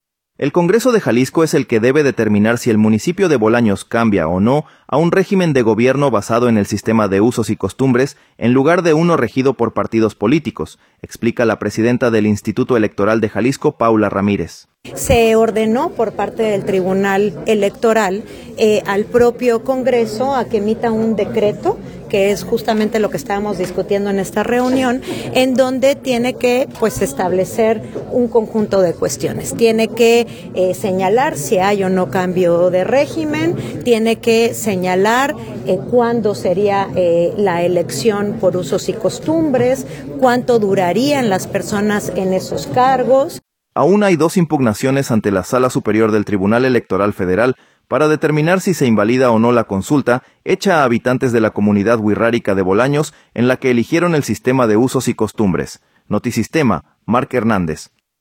El Congreso de Jalisco es el que debe determinar si el municipio de Bolaños cambia o no a un régimen de gobierno basado en el sistema de usos y costumbres en lugar de un regido por partidos políticos, explica la presidenta del Instituto Electoral de Jalisco, Paula Ramírez.